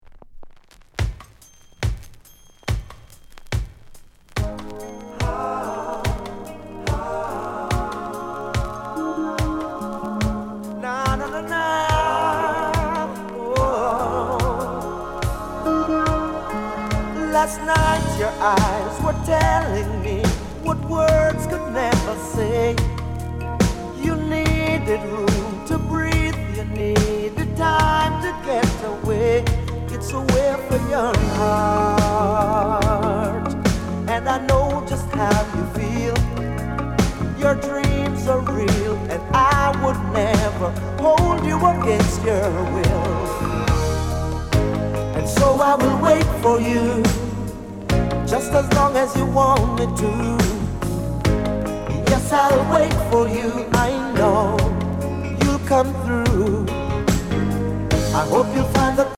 SOUND CONDITION VG